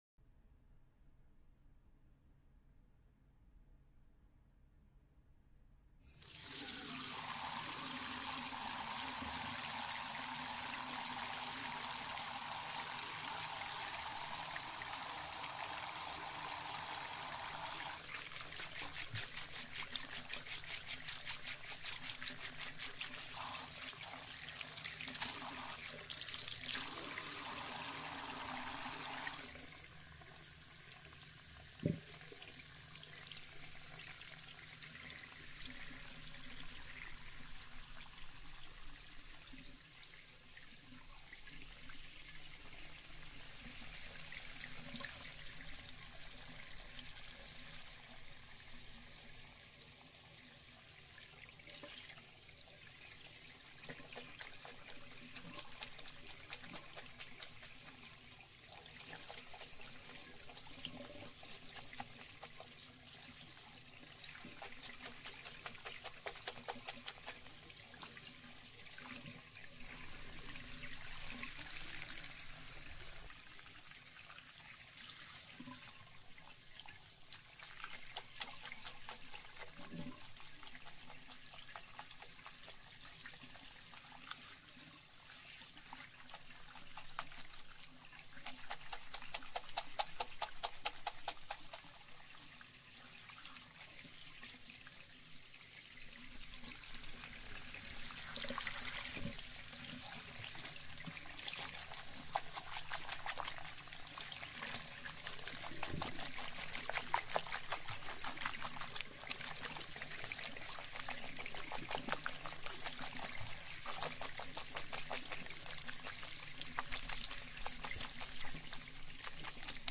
ASMR para dormir - Ducha y Shampoo